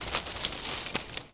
wood_tree_branch_move_19.wav